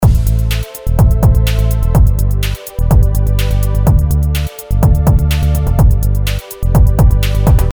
Bassline advice requested
Maybe shift the overall rhythmic composition so that the second kick/bass hit wouldn’t land on the 3th beat but 1/8 offsetted forwards.
(Sounds better than the first to my ears anyway)